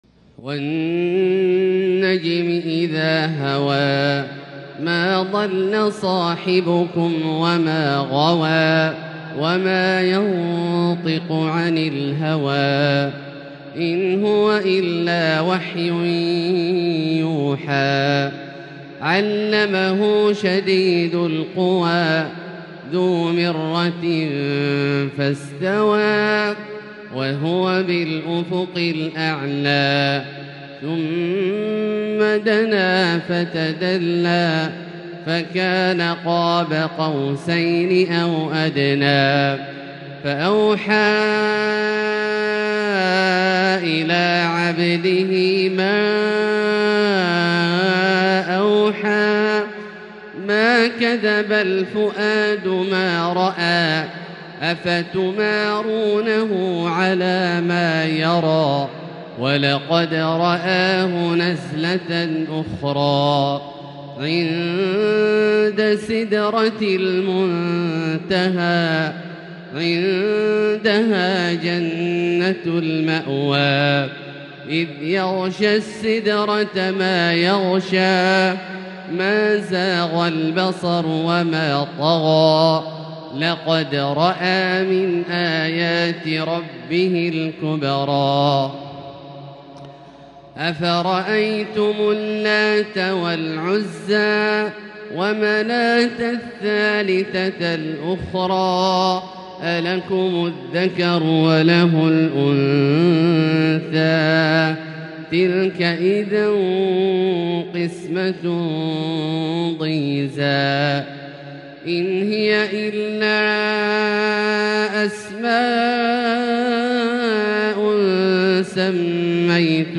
تلاوة رائقة لـ سورة النجم كاملة للشيخ د. عبدالله الجهني من المسجد الحرام | Surat An-Najm > تصوير مرئي للسور الكاملة من المسجد الحرام 🕋 > المزيد - تلاوات عبدالله الجهني